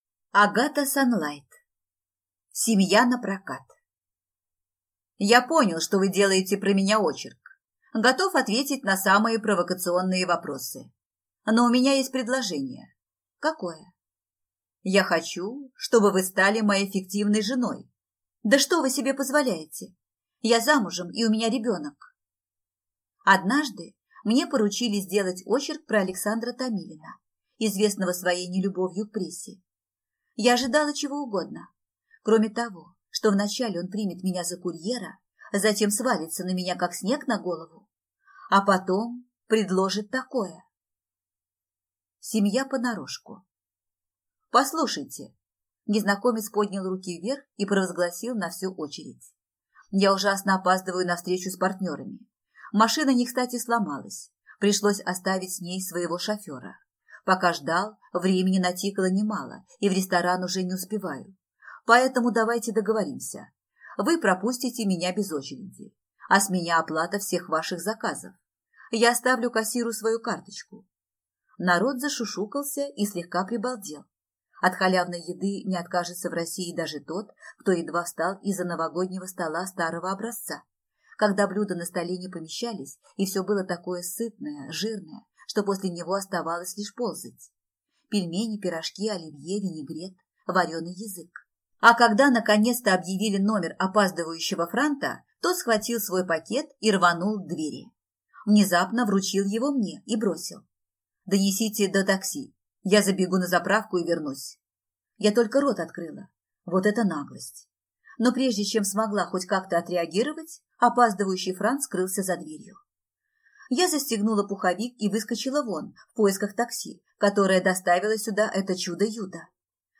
Аудиокнига Семья напрокат | Библиотека аудиокниг
Прослушать и бесплатно скачать фрагмент аудиокниги